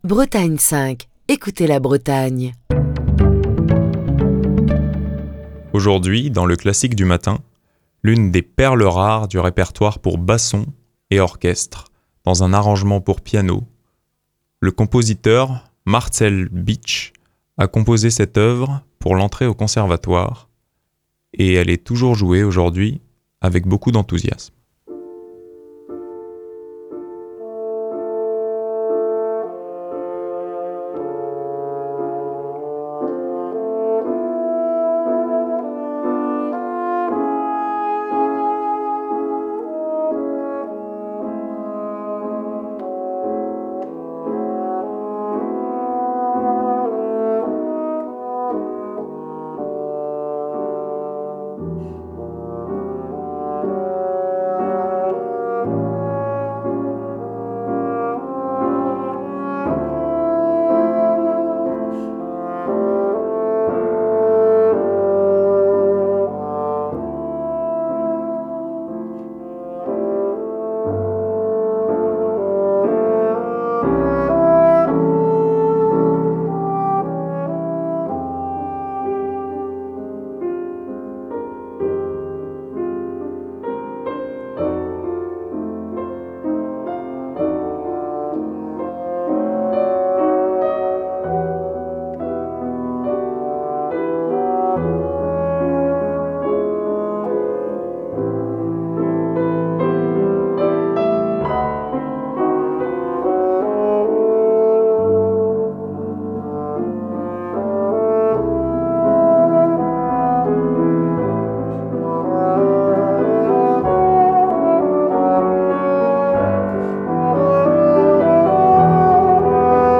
dans un arrangement pour piano